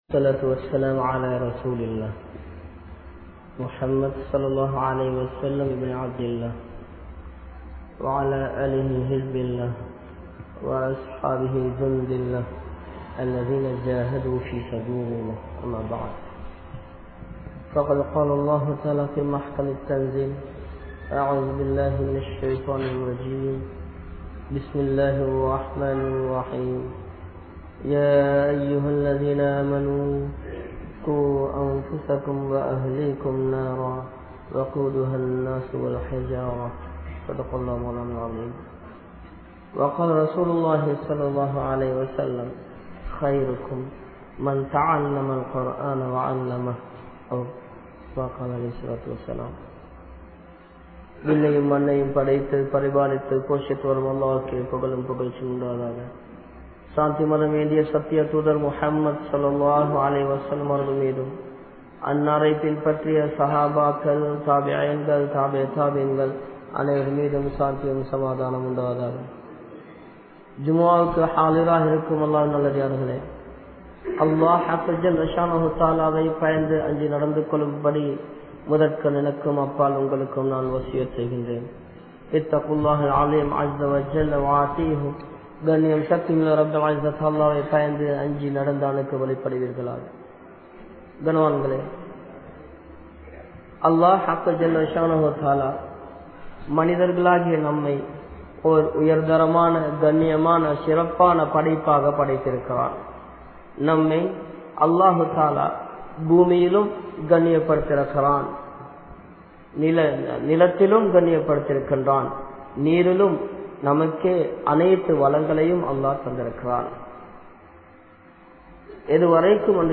Kulanthaihal Seeraliya Kaaranam Yaar? (குழந்தைகள் சீரழிய காரணம் யார்?) | Audio Bayans | All Ceylon Muslim Youth Community | Addalaichenai
Kurucoda Jumua Masjith